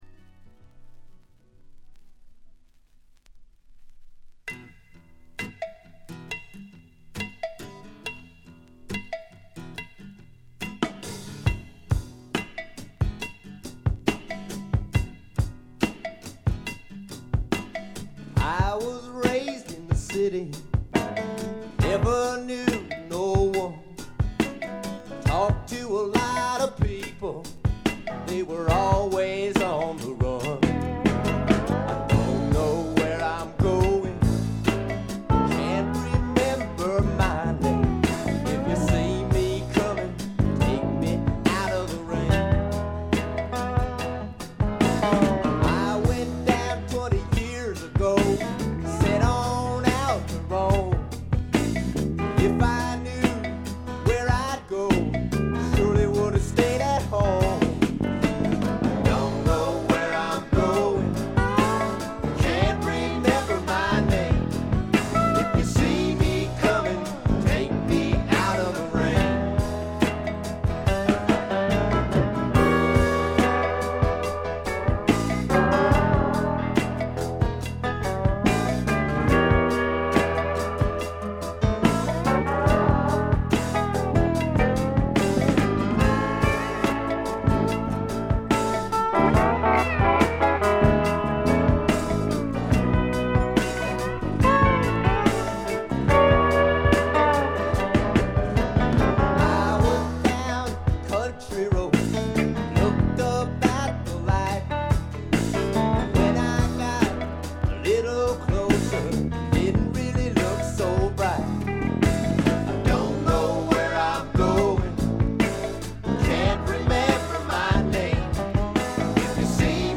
軽微なバックグラウンドノイズにチリプチ少々。
試聴曲は現品からの取り込み音源です。